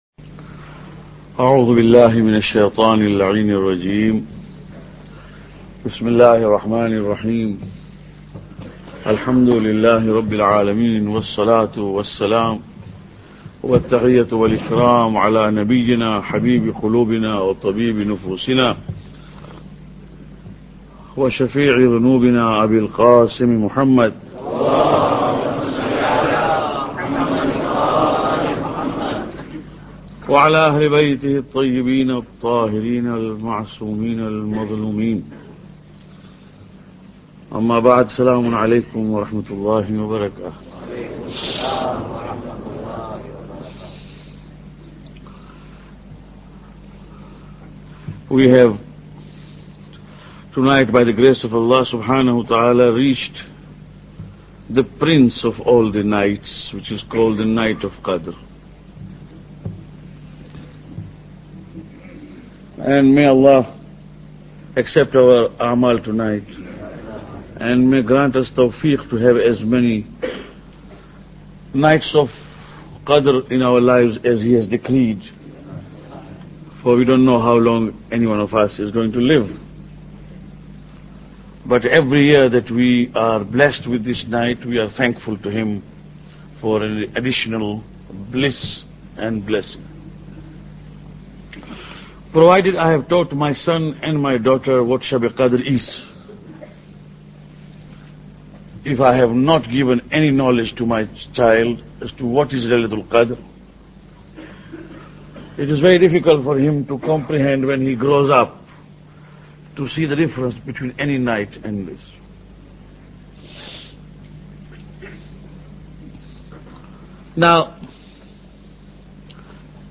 Lecture 20